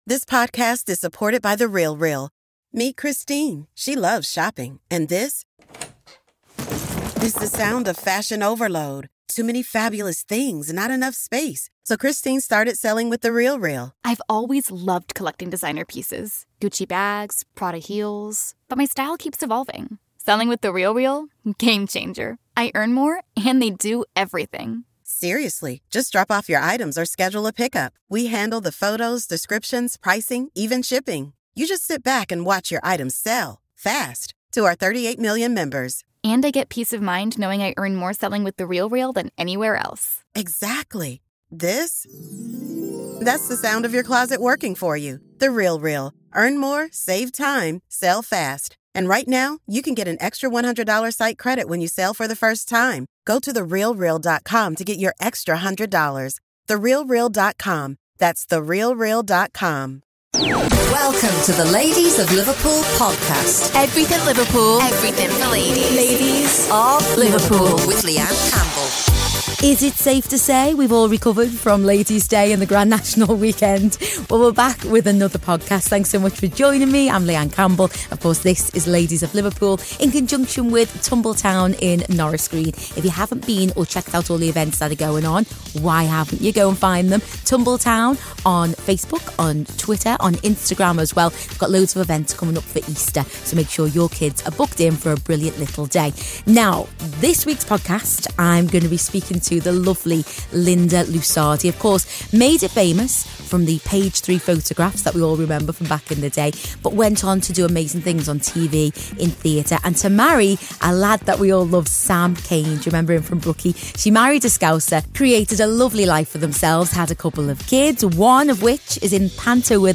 So I grabbed them both for a chat and they were just gorgeous in every way!